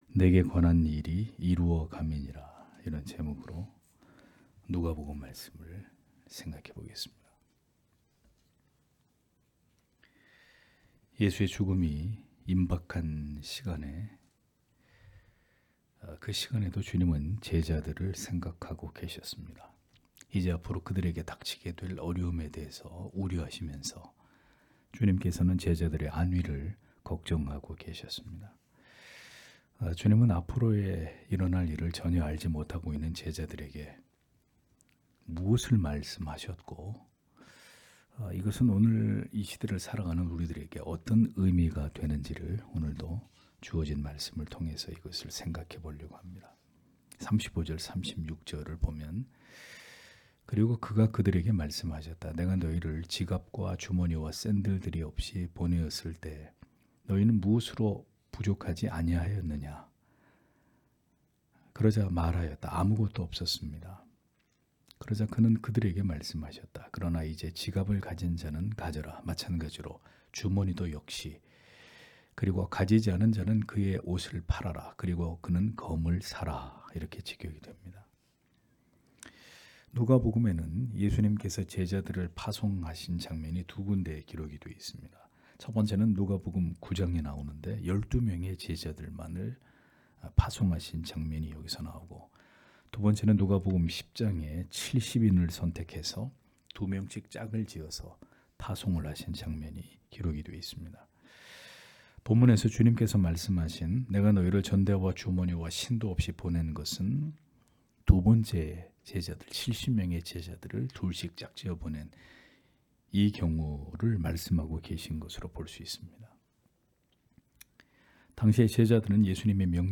금요기도회 - [누가복음 강해 167] '내게 관한 일이 이루어 감이니라' (눅 22장 35- 38절)